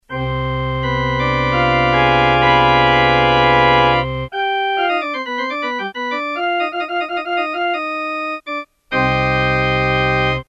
organs that desperately need reverb; you get the idea.
organ2.mp3